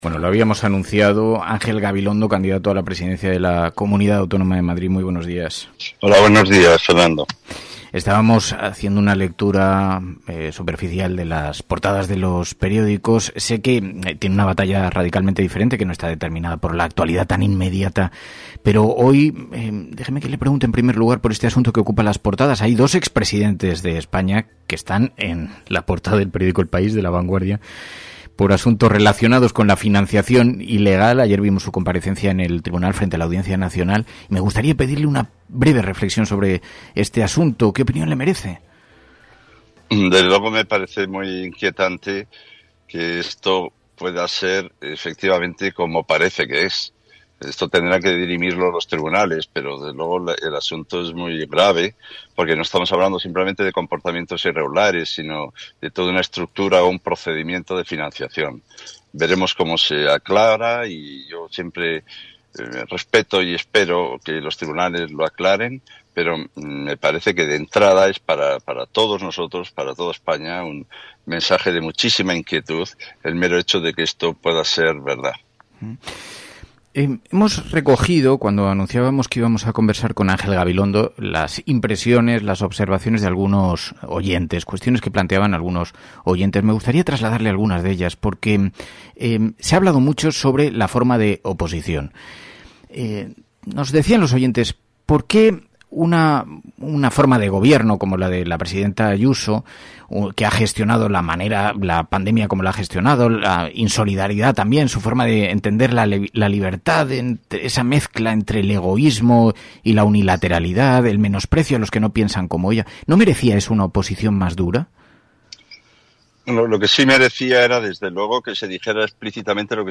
ENTREVISTA-ANGEL-GABILONDO.mp3